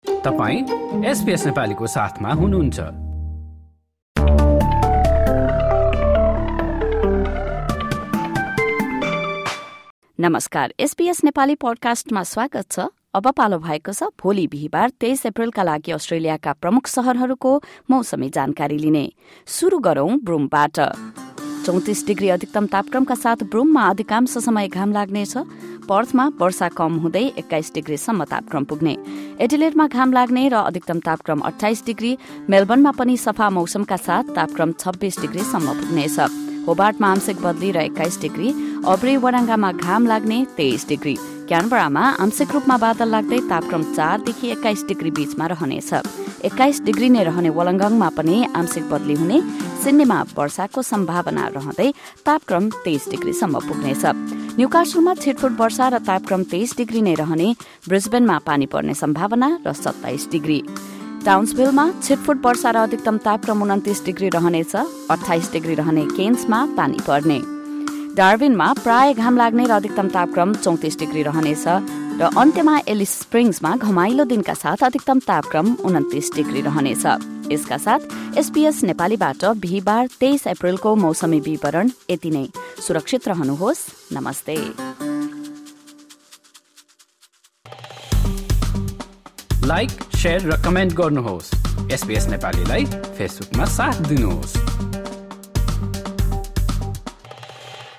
Weather update for major cities across Australia in Nepali. This update features tomorrow’s forecast for the following cities: Adelaide, Melbourne, Hobart, Albury-Wodonga, Sydney, Newcastle, Darwin and Alice Springs.